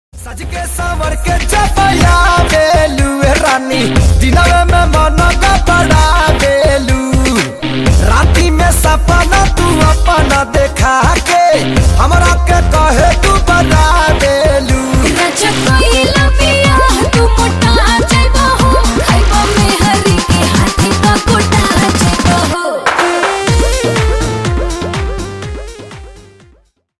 Romantic Bhojpuri hit